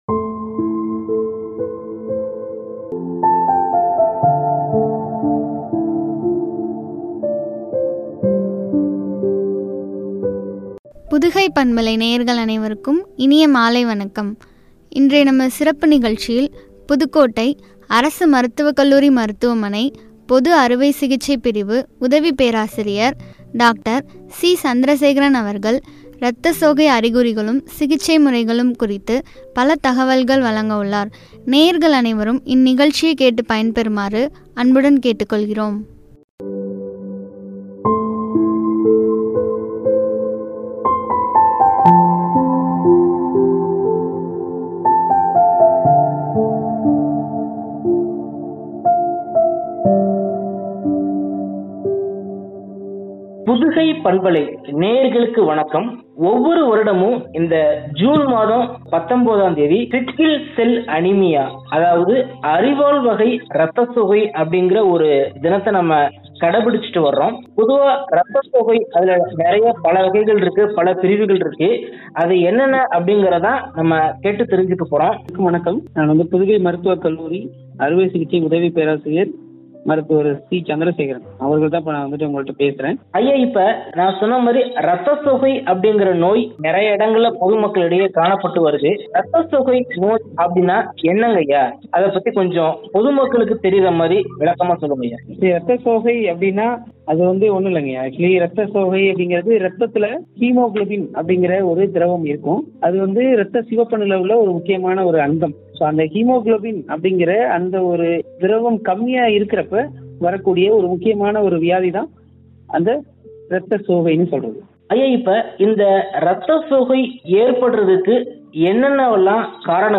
சிகிச்சை முறைகளும்” குறித்து வழங்கிய உரையாடல்.